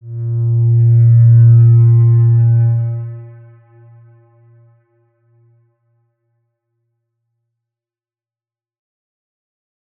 X_Windwistle-A#1-ff.wav